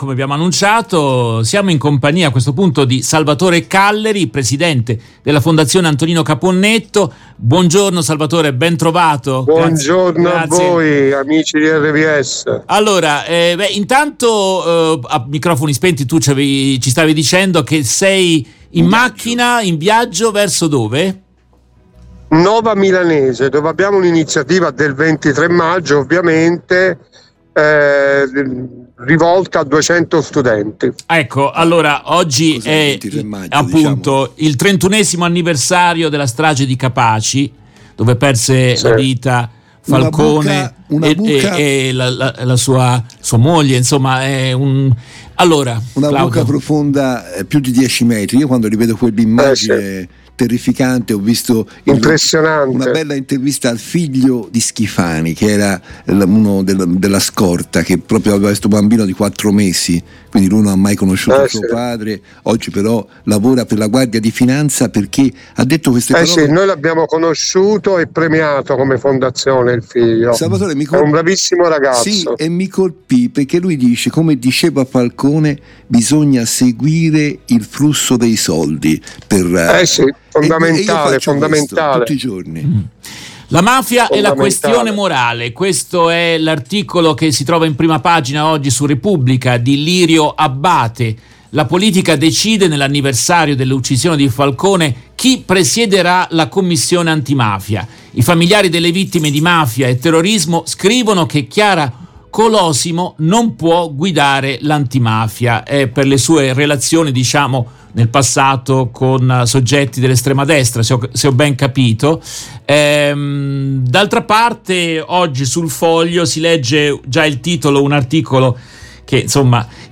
In questa intervista tratta dalla diretta RVS del 23 maggio 2023